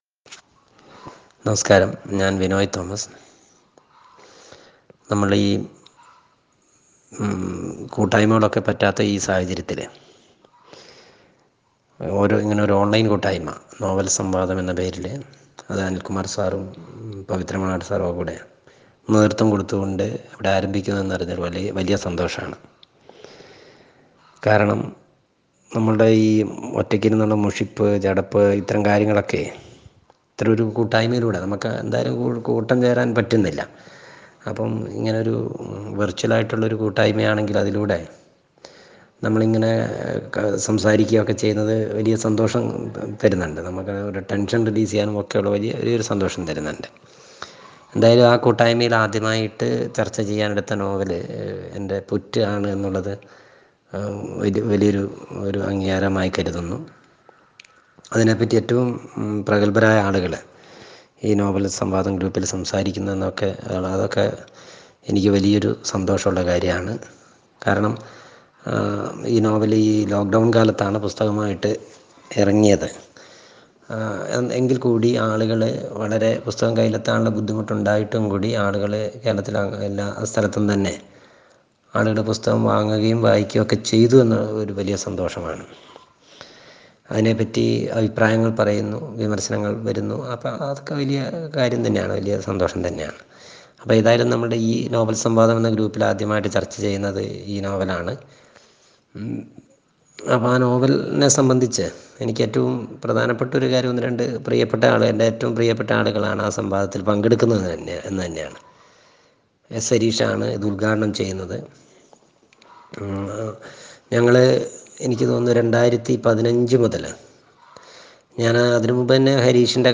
പുറ്റ് എന്ന നോവൽ എഴുതാൻ പ്രചോദനമായത് എസ് ഹരീഷിന്റെ മീശ എന്ന നോവൽ ആണെന്ന് മലയാളിയുടെ പ്രിയപ്പെട്ട എഴുത്തുകാരൻ വിനോയ് തോമസ്. ഒരു ഓൺലൈൻ കൂട്ടായ്മ സംഘടിപ്പിച്ച നോവൽ ചർച്ചയിൽ ‘പുറ്റ് ‘ എന്ന നോവലിന്റെ എഴുത്തനുഭവങ്ങൾ പങ്കുവെക്കുകയായിരുന്നു അദ്ദേഹം.